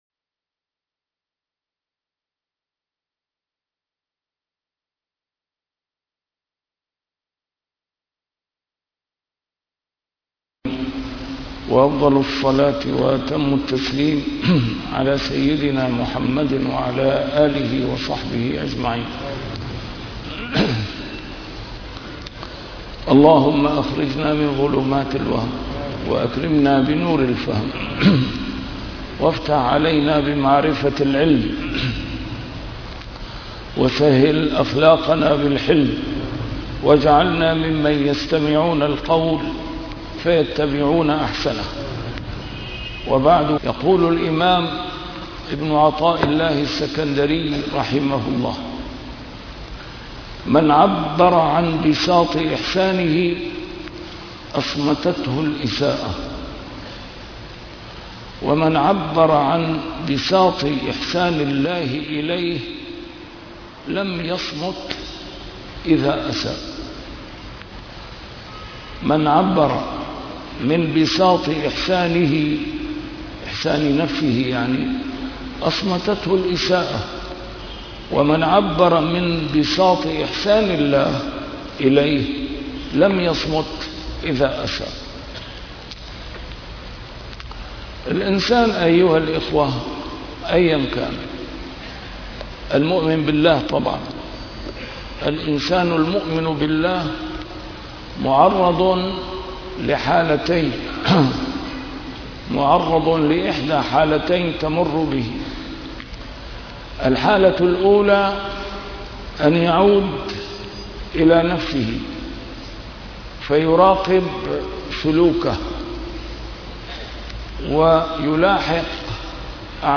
A MARTYR SCHOLAR: IMAM MUHAMMAD SAEED RAMADAN AL-BOUTI - الدروس العلمية - شرح الحكم العطائية - الدرس رقم 201 الحكمة رقم 180